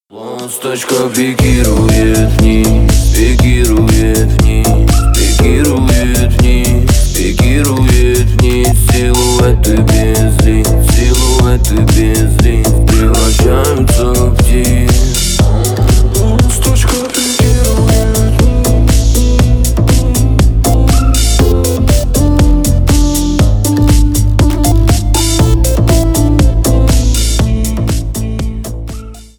рэп , хип-хоп